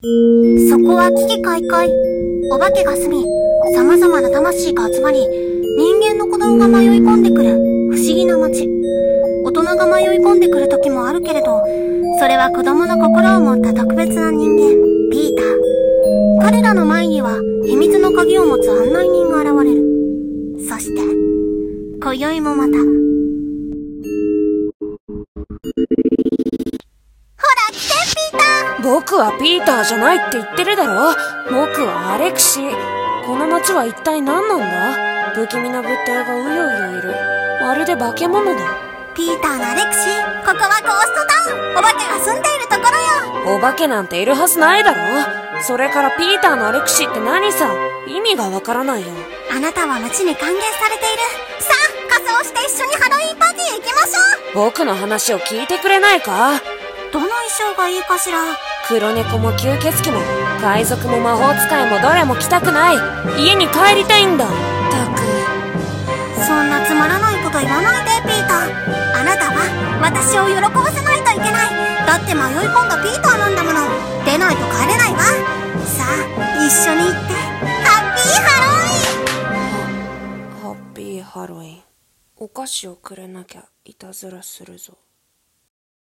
【声劇】a.k.a. Peterと嗤う愉快な案内人【掛け合い】